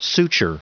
Prononciation du mot suture en anglais (fichier audio)
Prononciation du mot : suture